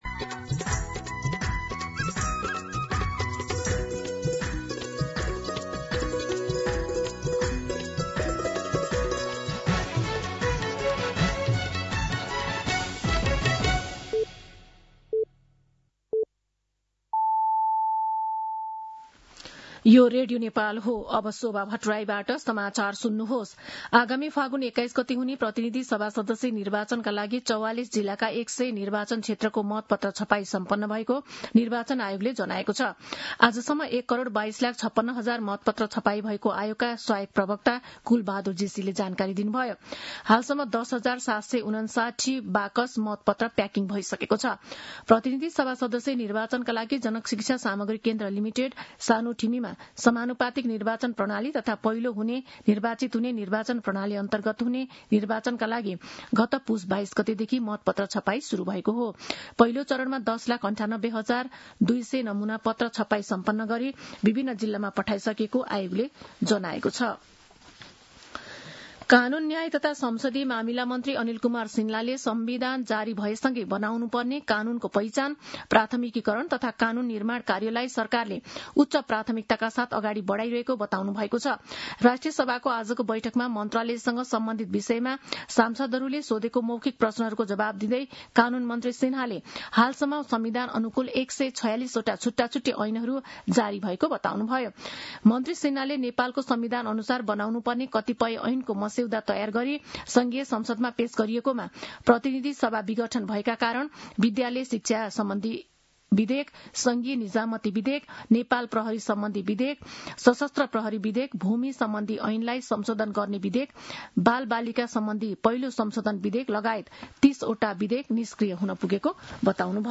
दिउँसो ४ बजेको नेपाली समाचार : २६ माघ , २०८२